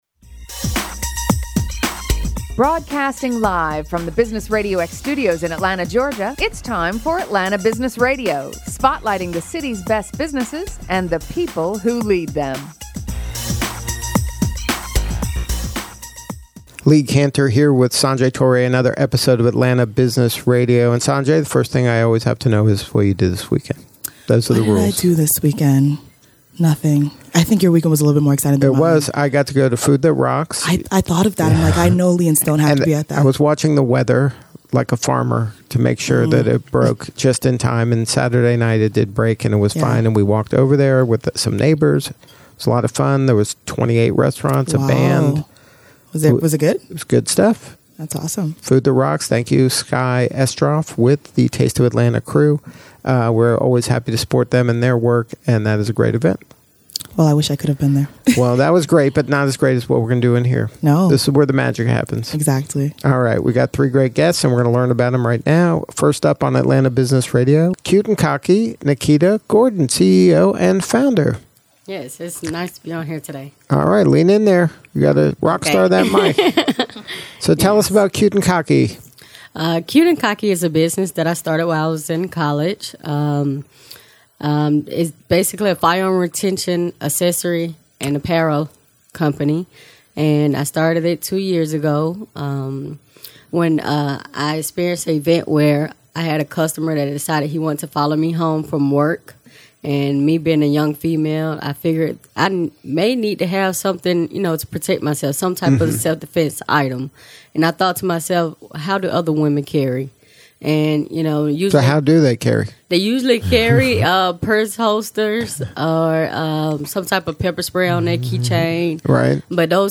Interviewed by Atlanta Business Radio X Media Crew